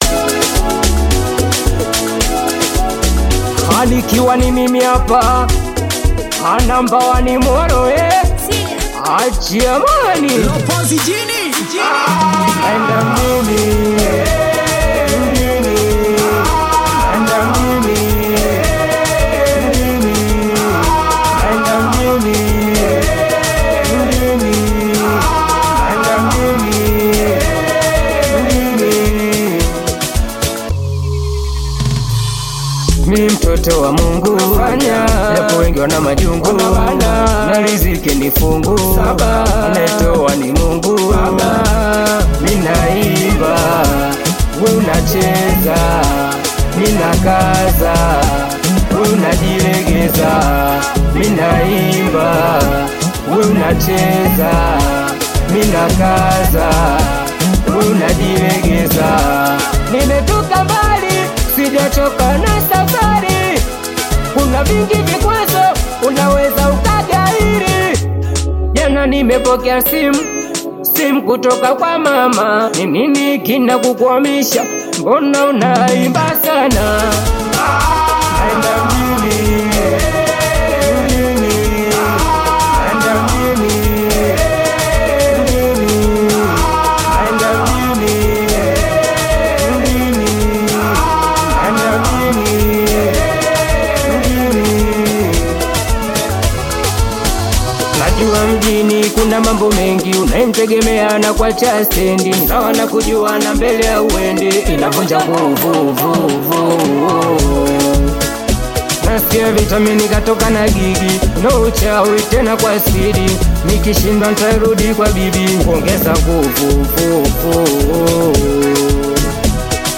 vibrant Tanzanian Singeli/Bongo Flava single